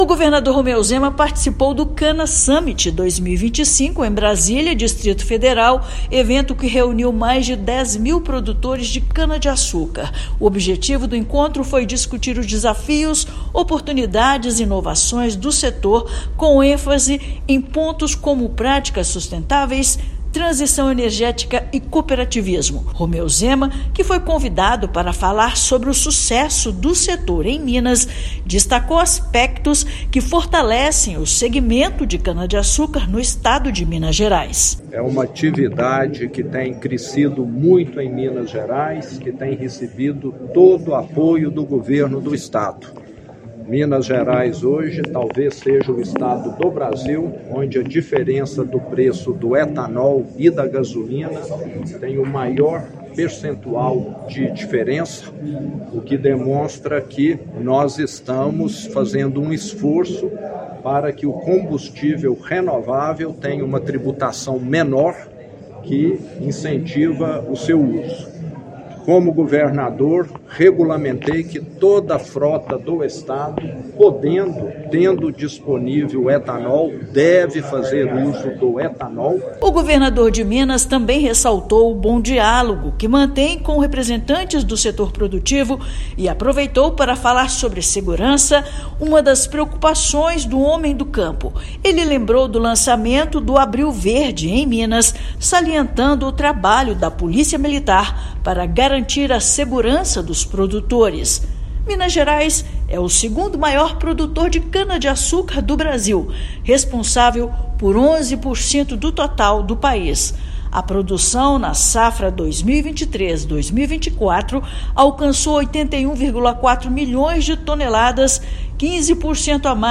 Segundo maior produtor do Brasil, a cana-de-açúcar produzida no estado representa 11% de todo o país. Ouça matéria de rádio.